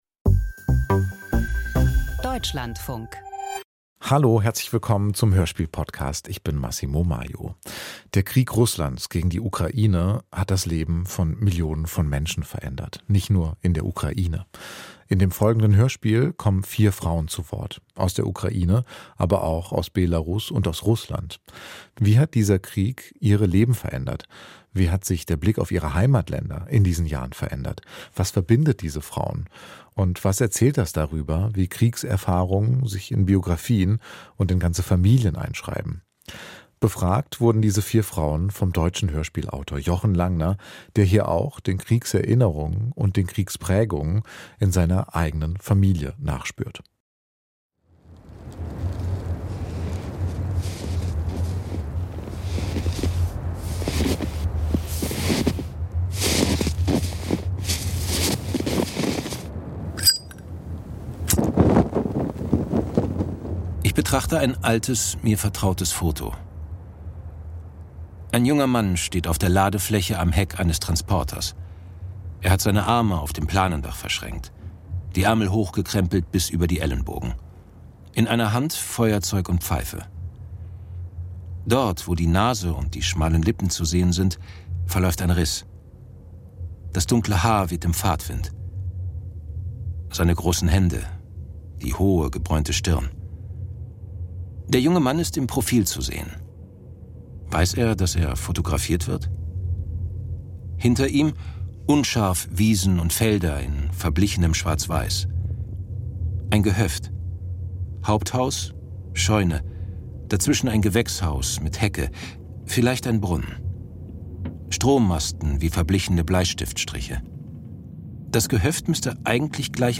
Winterlied - Dokumentarisches Hörspiel ~ Hörspiel Podcast